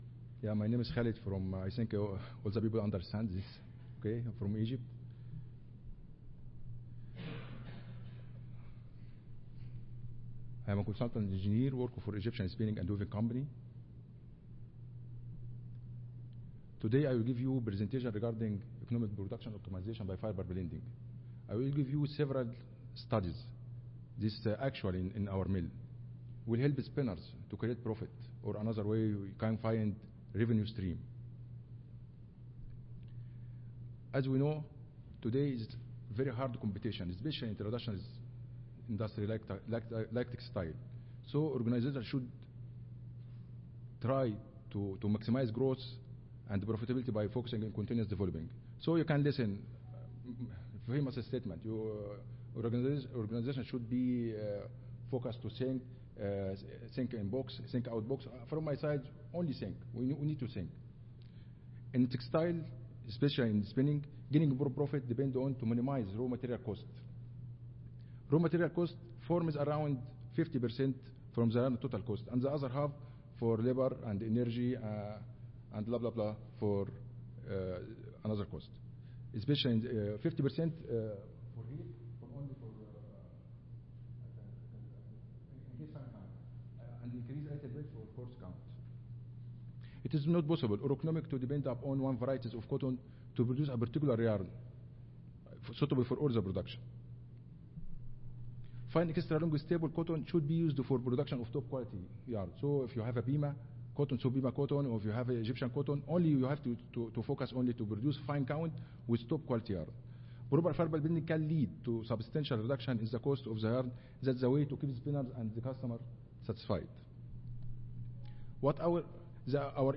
Salon C (Marriott Rivercenter Hotel)
Recorded Presentation